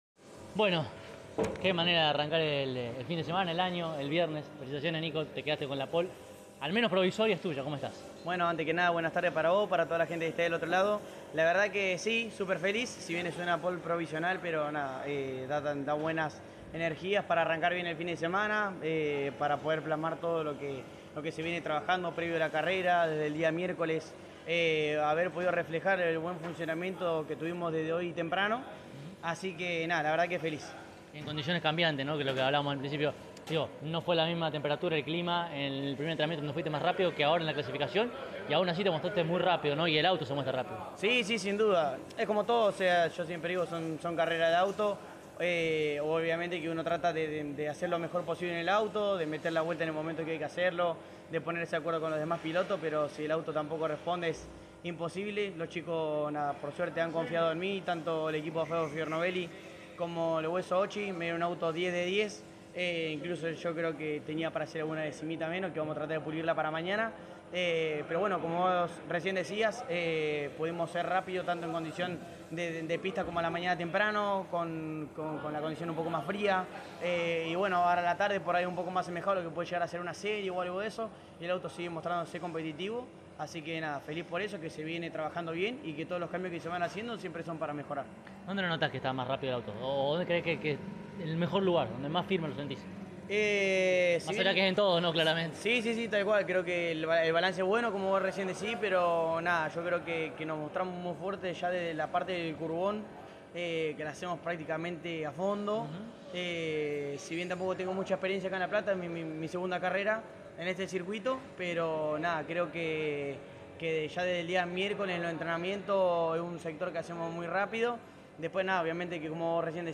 en diálogo exclusivo con CÓRDOBA COMPETICIÓN